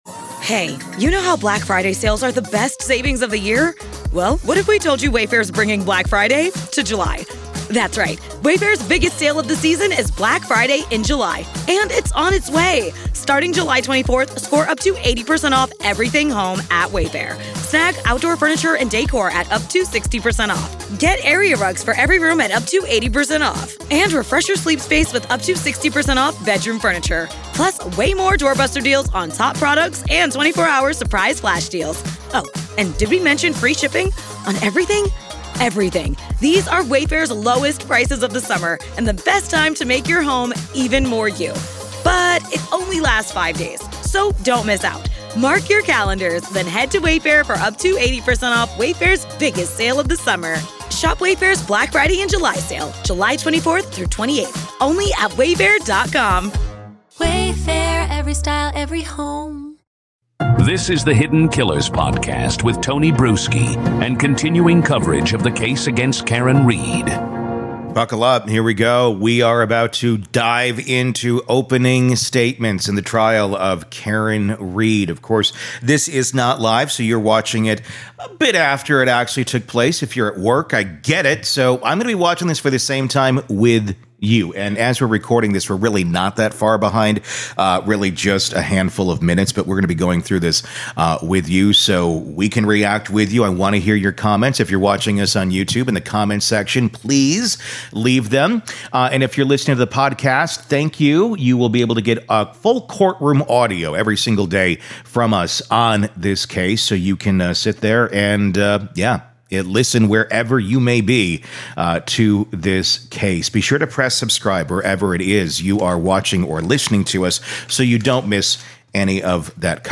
In this video, you'll watch the complete opening arguments delivered by special prosecutor Hank Brennan, who outlines the state's case alleging that Read intentionally struck O'Keefe with her SUV and left him to die in a snowstorm.